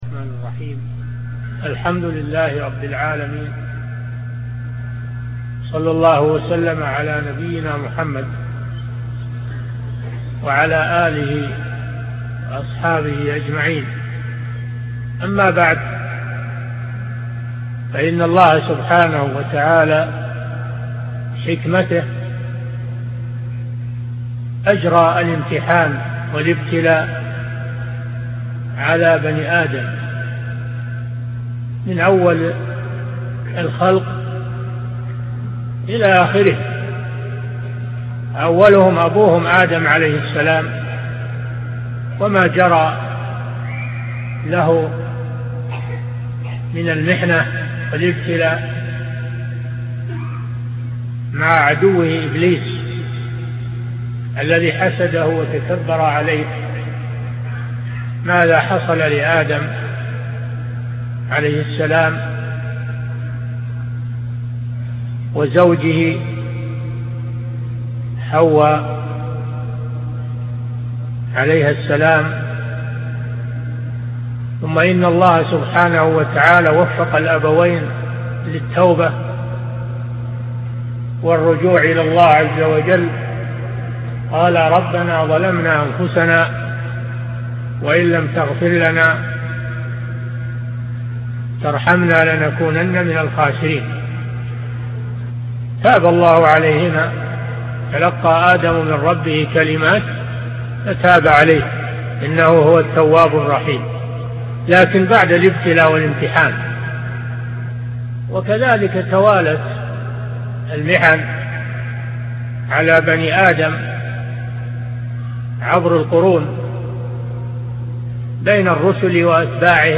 محاضرة / أسباب النجاة من الفتن - الشيخ صالح الفوزان